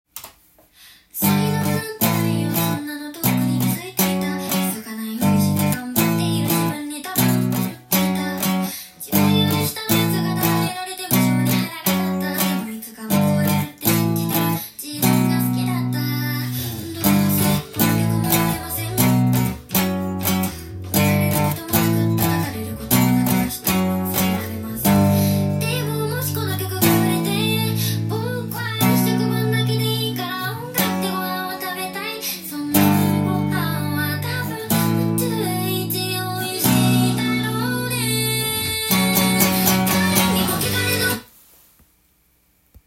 音源にあわせて弾いてみました
すべてパワーコードで譜面にしてみました。
付点８分音符と１６分音符。
リズムだけでも良い感じのギターパートです。